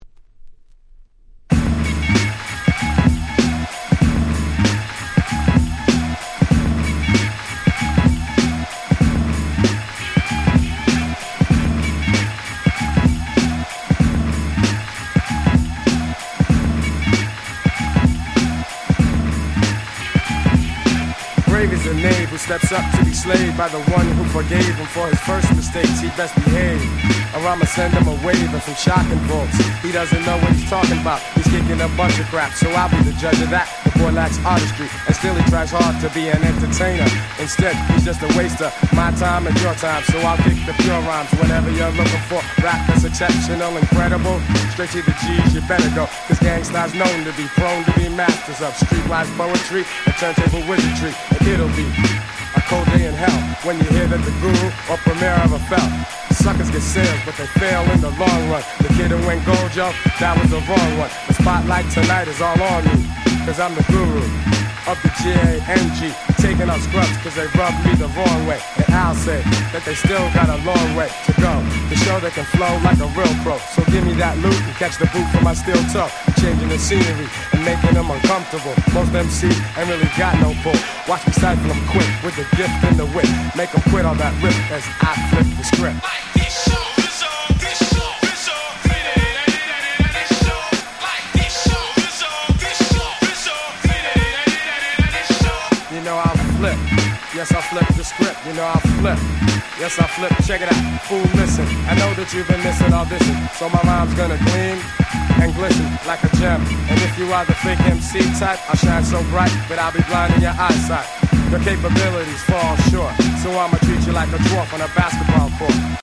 92' Super Classic Hip Hop !!